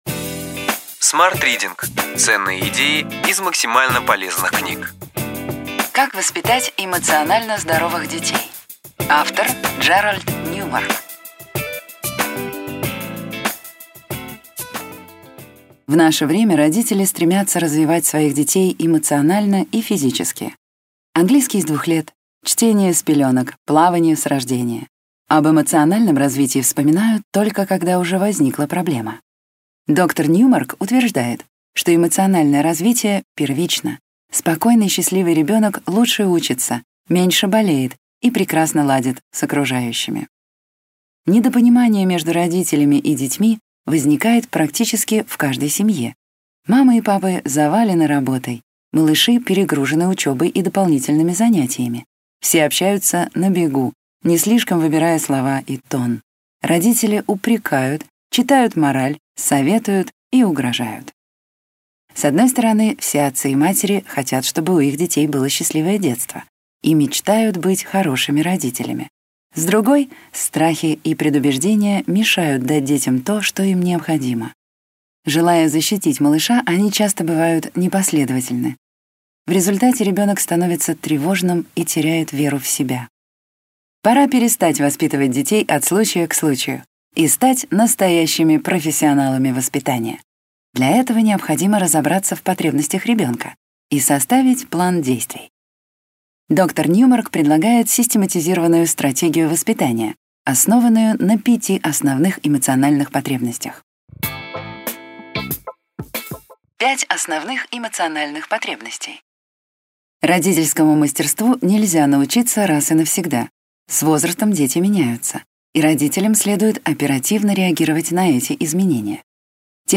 Аудиокнига Ключевые идеи книги: Как воспитать эмоционально здоровых детей.